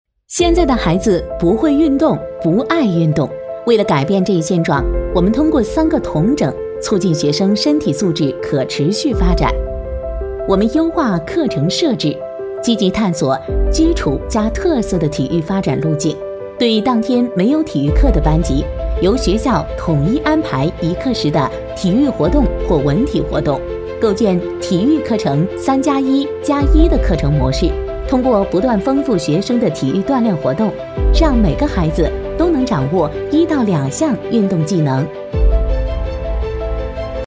配音风格： 感情丰富，自然活力
【课件】学校治理